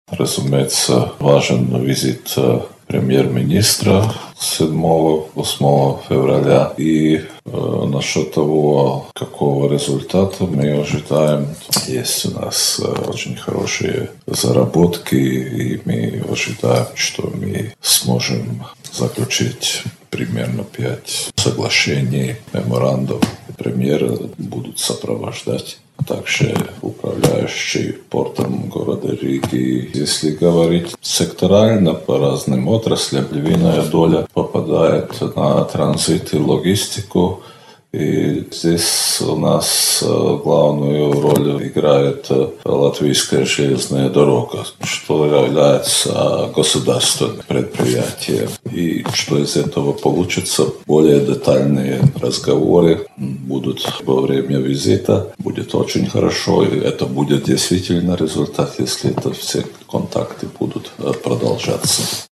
Совместных тем немало в сфере туризма и образования, отмечает посол Латвии в Беларуси Мартиньш Вирсис.